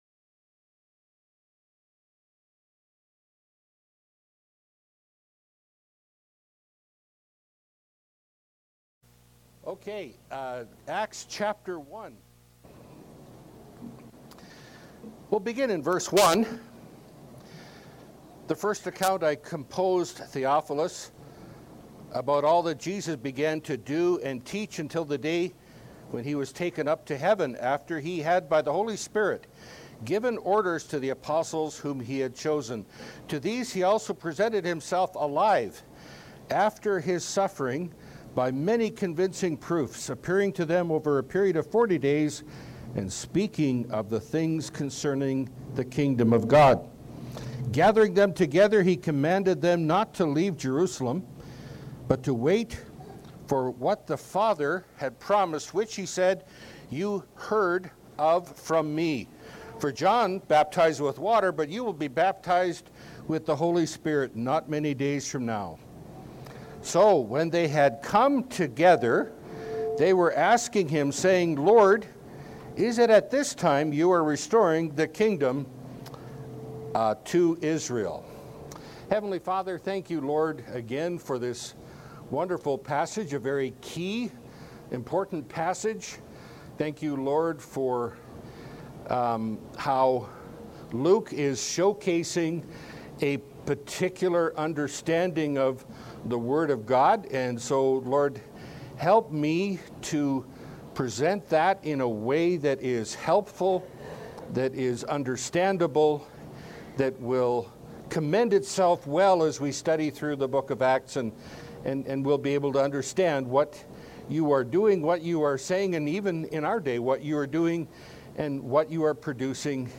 Pulpit Sermons